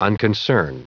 Prononciation du mot unconcern en anglais (fichier audio)
Prononciation du mot : unconcern